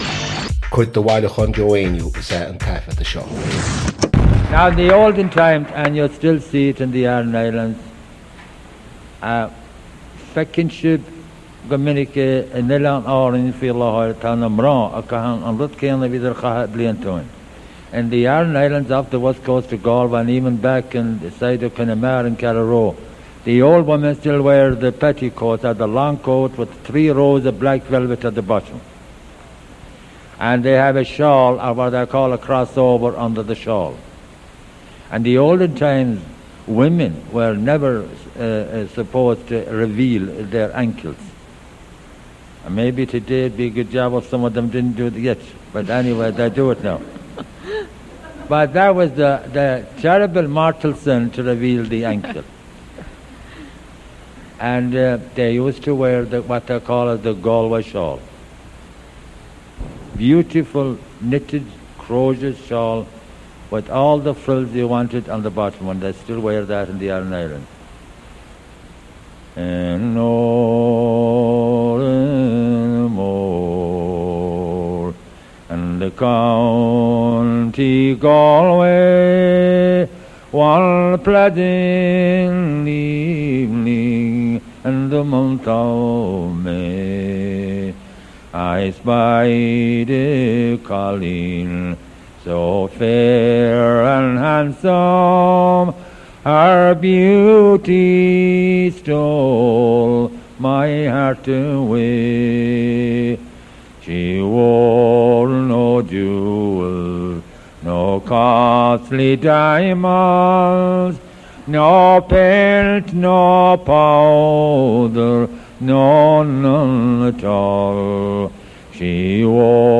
• Catagóir (Category): Song.
• Ainm an té a thug (Name of Informant): Joe Heaney.
• Suíomh an taifeadta (Recording Location): San Francisco, California, United States of America.